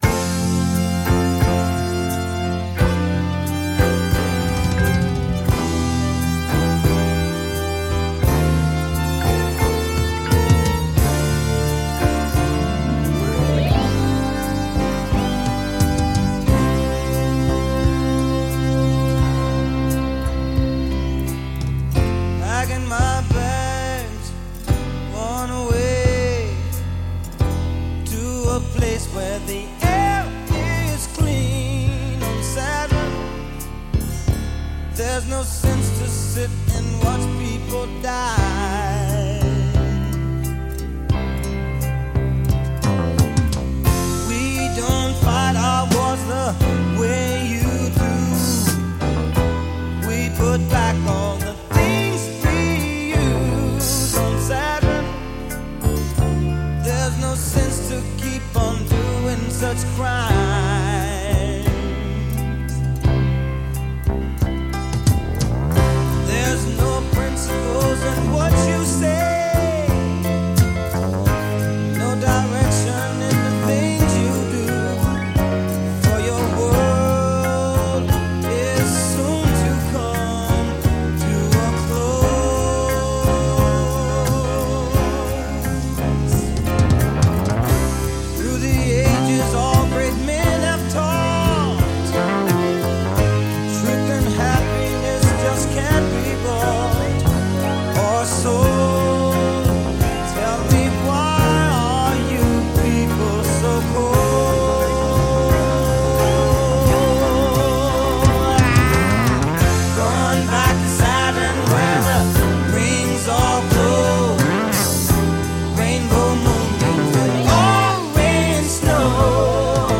which can only be described as Sci-Fi soul.